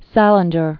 (sălĭn-jər), J(erome) D(avid) 1919-2010.